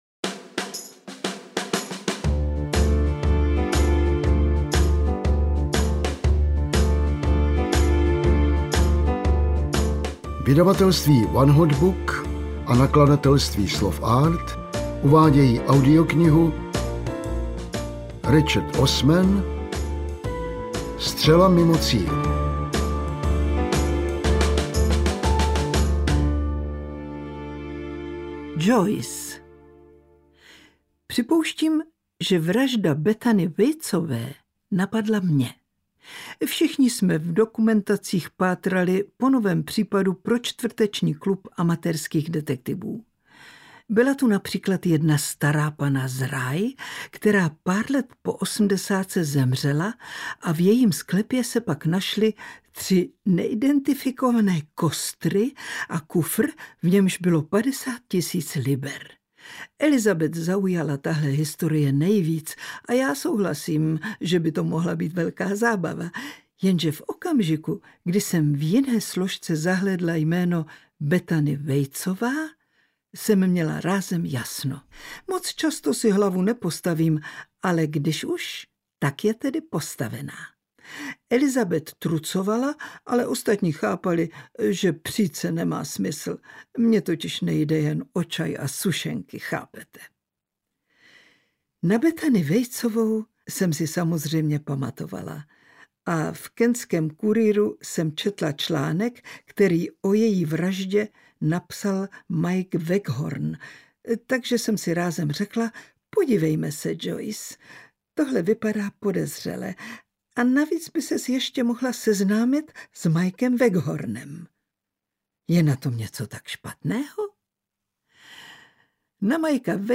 Ukázka z knihy
• InterpretLibuše Švormová, Jan Vlasák